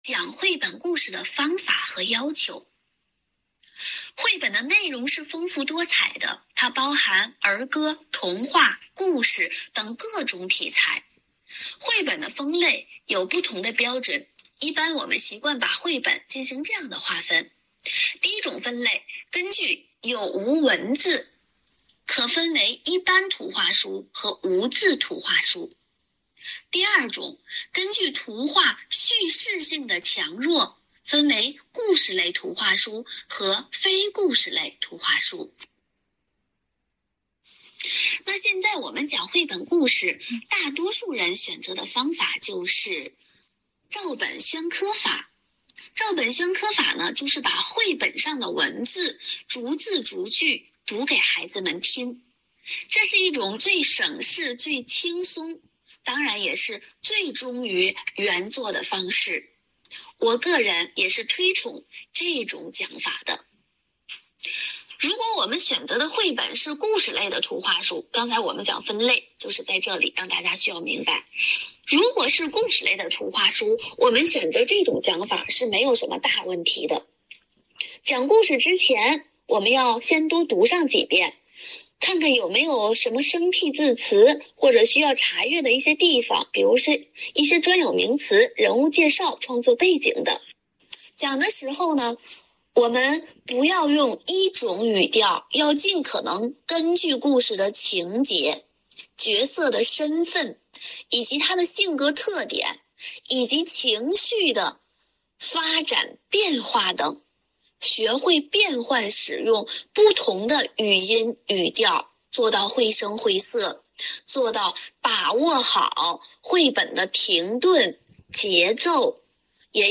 活动地点：线上
专题讲座：如何讲绘本故事(第二期）